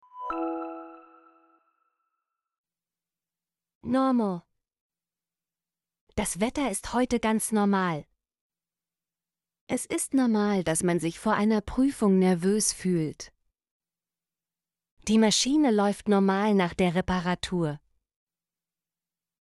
normal - Example Sentences & Pronunciation, German Frequency List